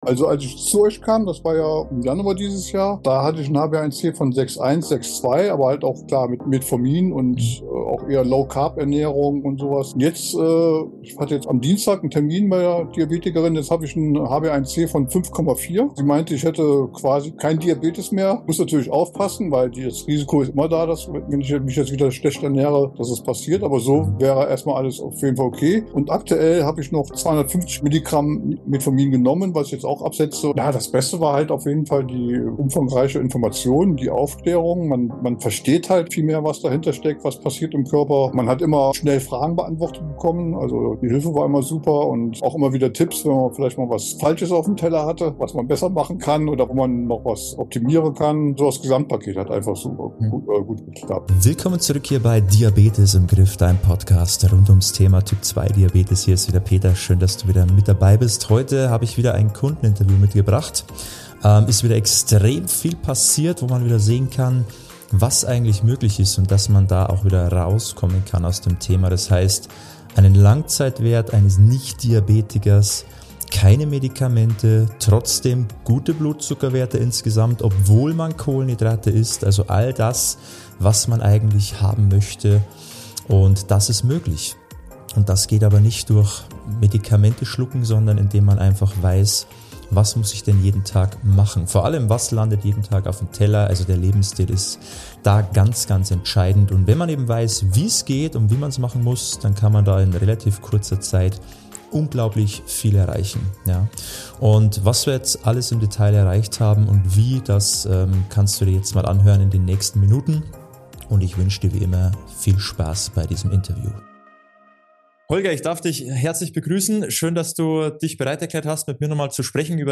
Ein ehrliches Kundengespräch über Ursachenarbeit, Dranbleiben und nachhaltige Veränderungen.